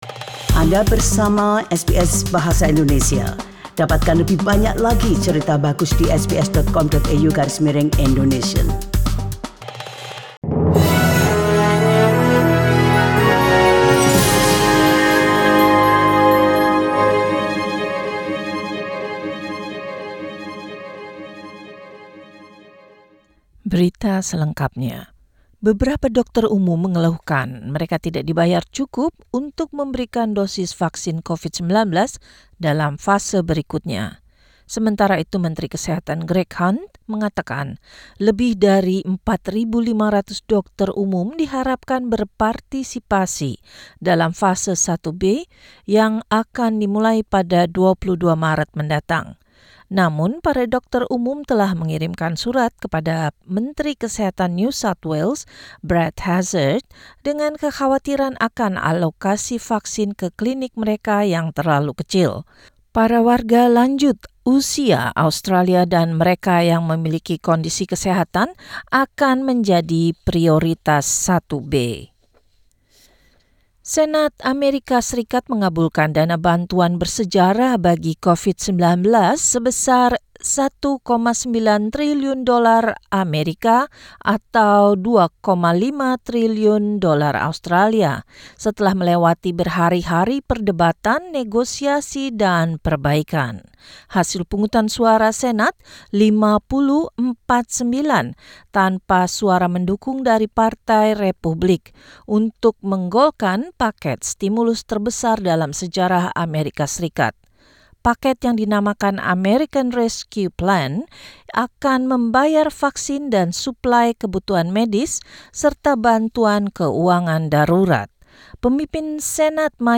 SBS Radio News in Indonesian - 7 March 2021